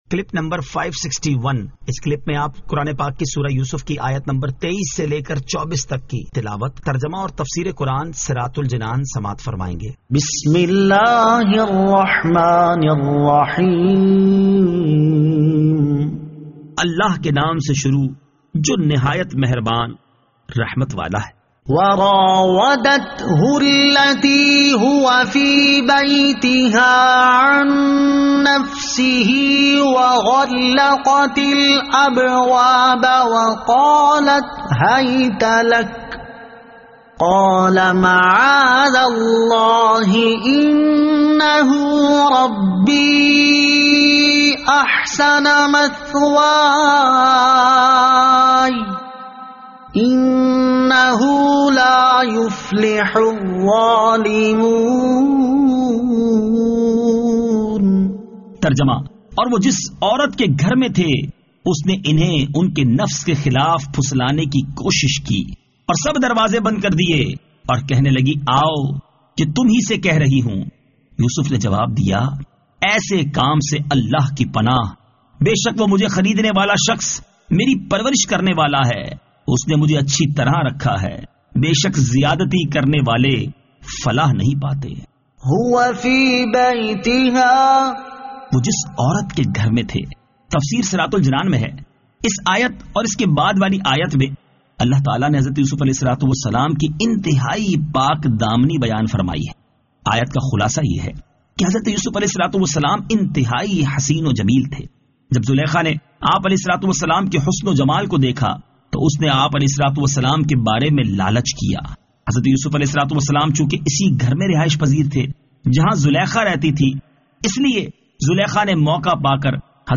Surah Yusuf Ayat 23 To 24 Tilawat , Tarjama , Tafseer